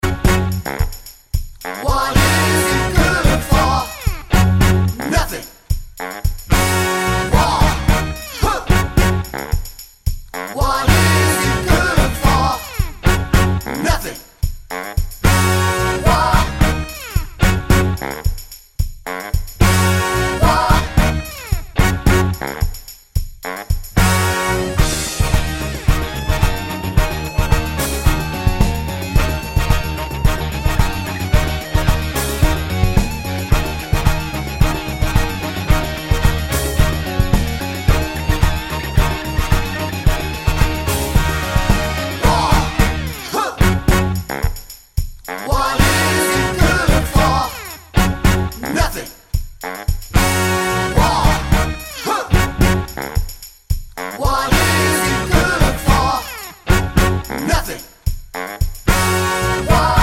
no Backing Vocals Soul / Motown 3:20 Buy £1.50